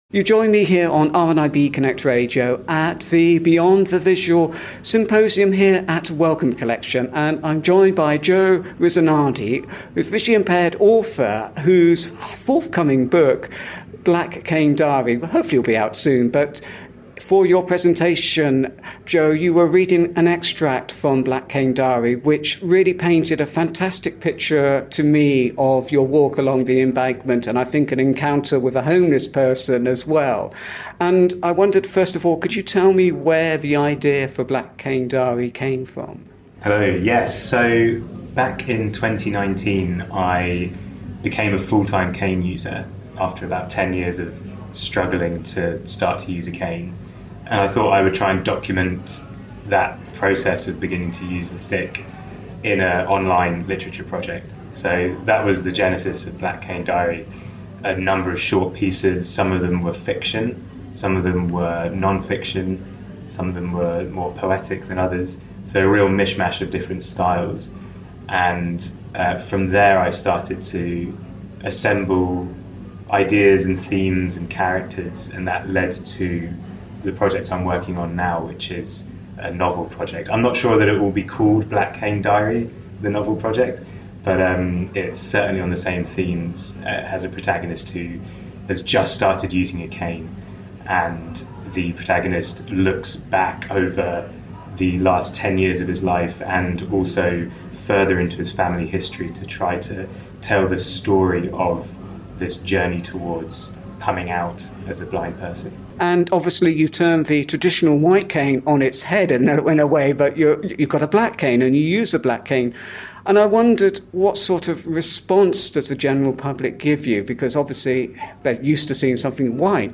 Beyond the Visual, a symposium reflecting on what blindness brings to the experience of art within cultural organisations and beyond was held at Wellcome Collection on Friday and Saturday 21 and 22 October 2022.